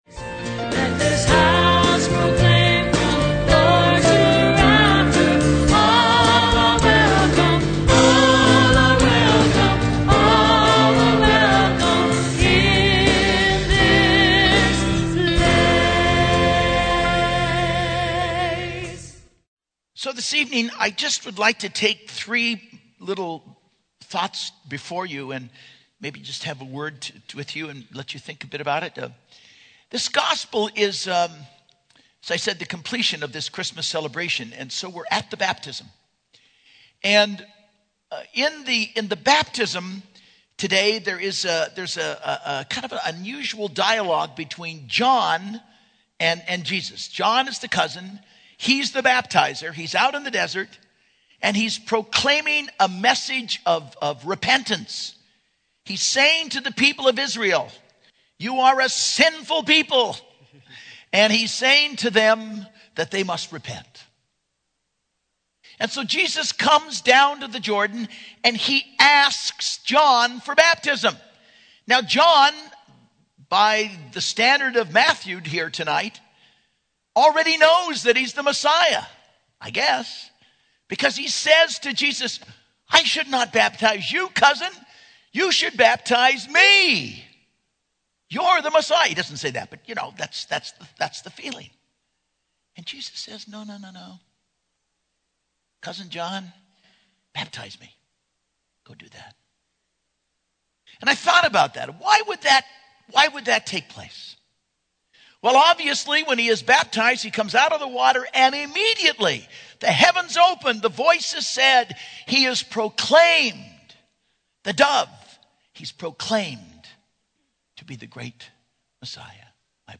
Homily - 1/9/11 - Baptism of the Lord